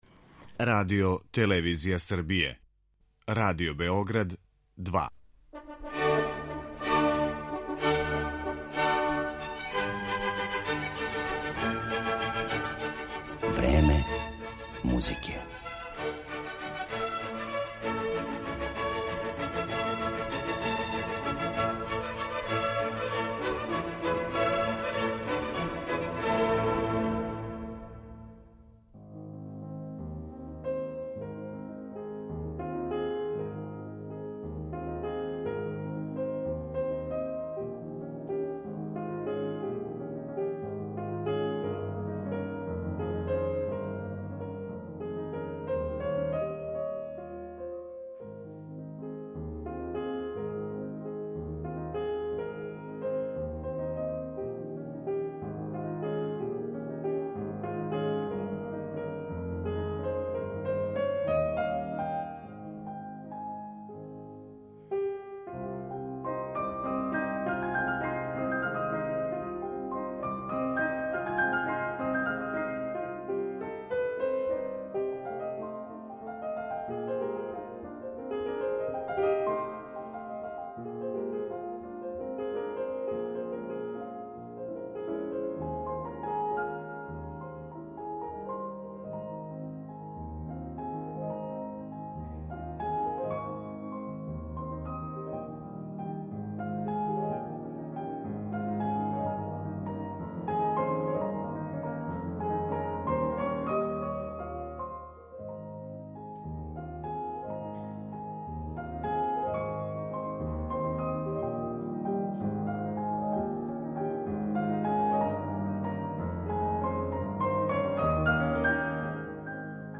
Гошће емисије Време музикe биће чланице клавирског Дуа Бизјак ‒ сестре Лидија и Сања Бизјак. Уметнице ће најавити концерт у Коларчевој задужбини, где ће наступити уз ансамбл „Музикон”, као и наступ на Сомборским музичким свечаностима, а осврнућемо се и на њихову успешну дугогодишњу каријеру.